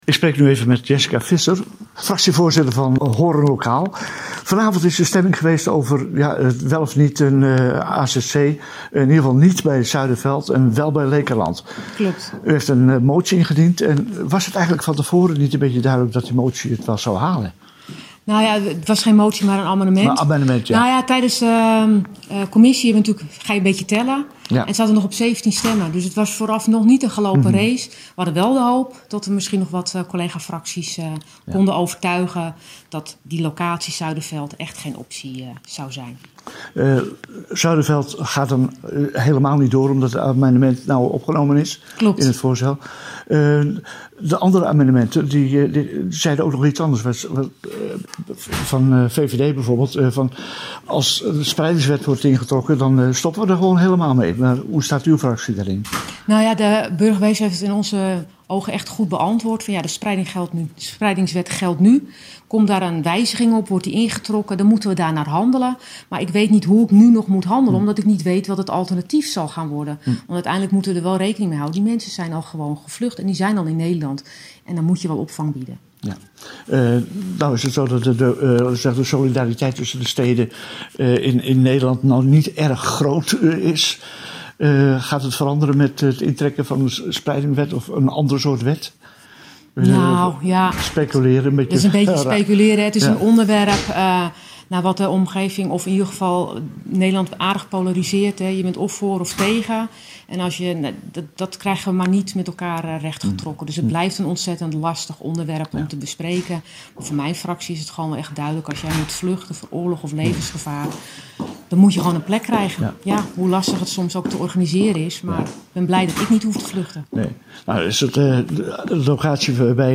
Gesprek met Jessica Visser (Fractievoorzitter Hoorn Lokaal) over AZC locatiekeuze Leekerlanden: